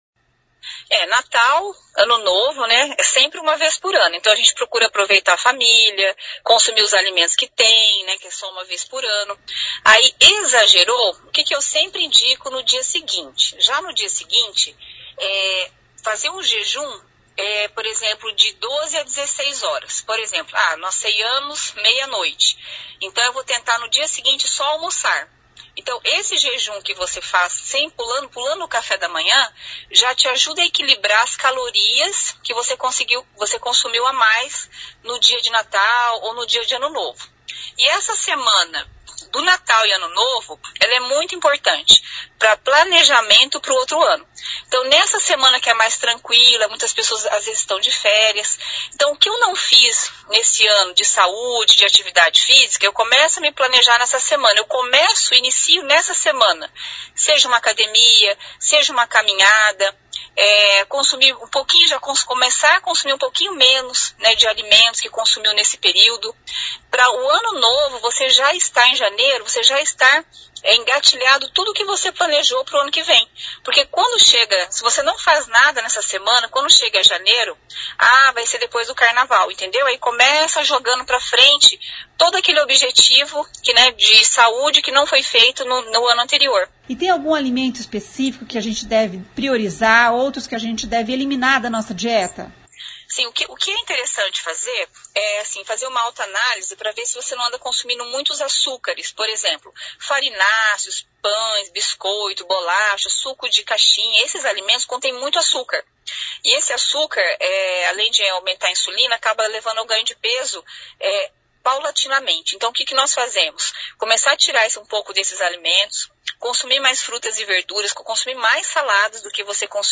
Ouça o que diz a nutricionista: